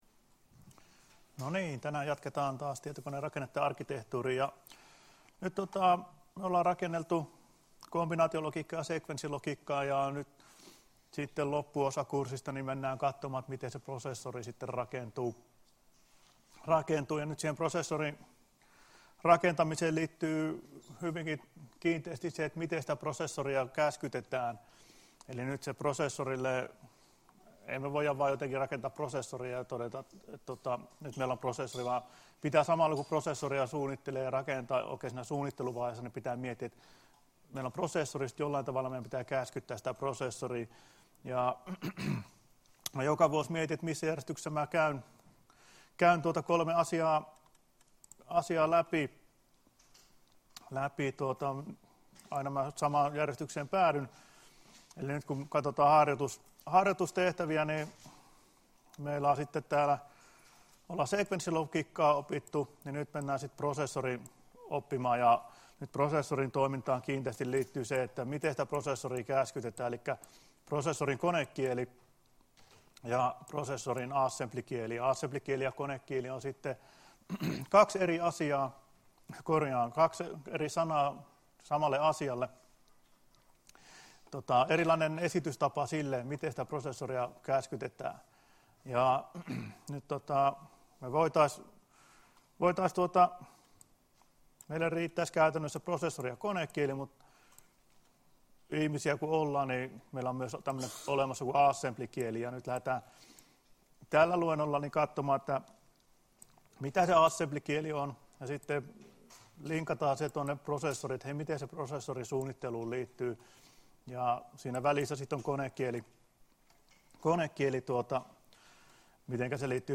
Luento 27.11.2017 — Moniviestin